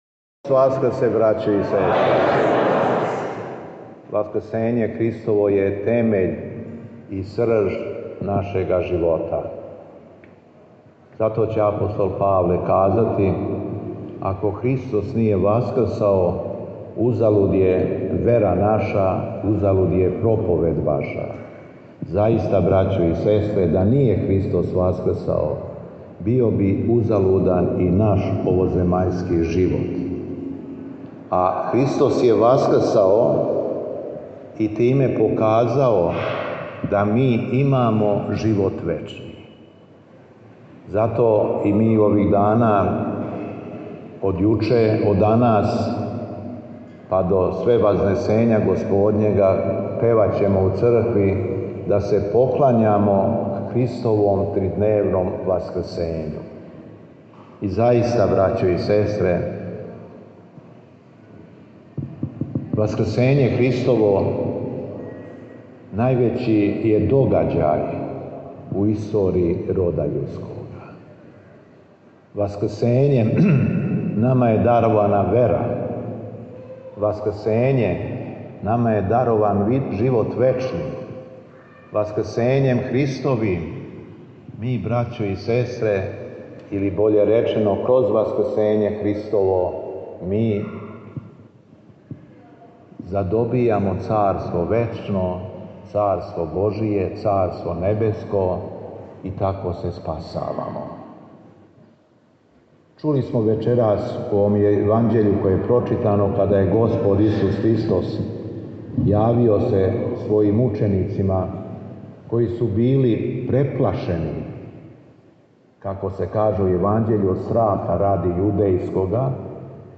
О Васкрсу, 20. априла 2025. године, Његово Високопреосвештенство Архиепископ крагујевачки и Митрополит шумадијски Господин Јован, служио је Пасхално вечерње у цркви Светих апостола Петра и Павла у Јагодини, како налаже давно установљена пракса у овој богом спасаваној православној дијецези.
Беседа Његовог Високопреосвештенства Митрополита шумадијског г. Јована